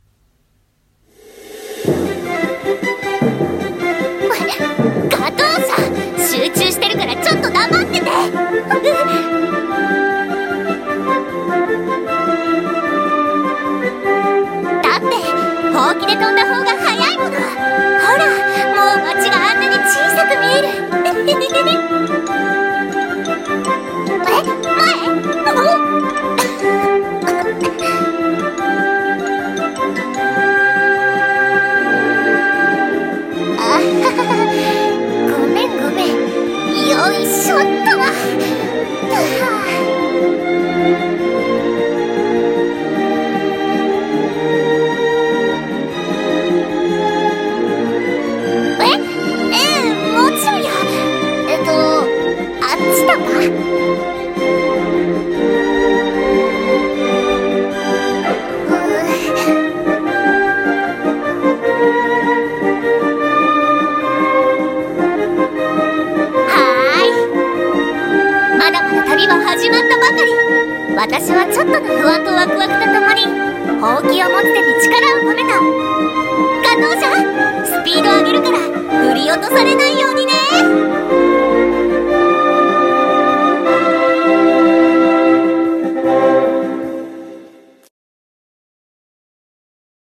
【声劇】魔女と猫と遥かなる空